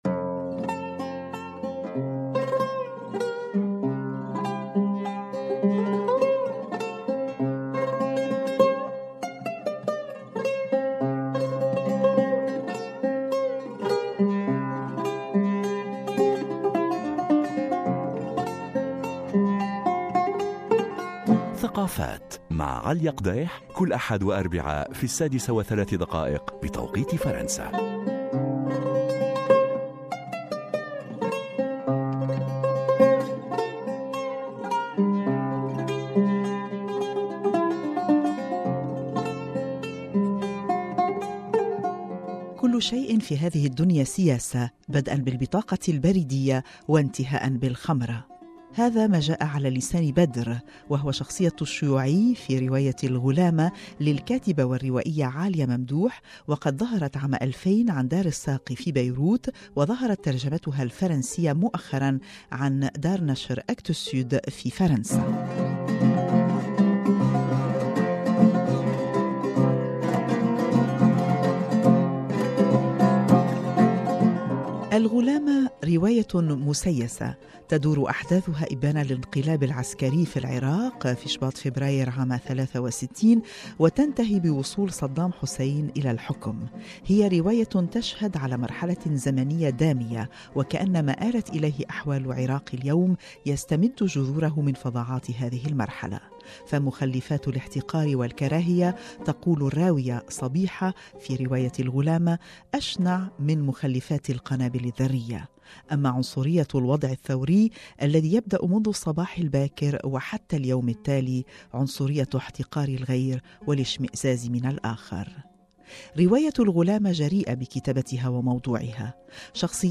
حوار مع اذاعة مونت كارلو